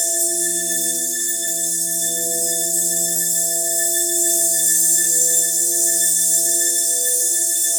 shimmer_sparkle_loop_01.wav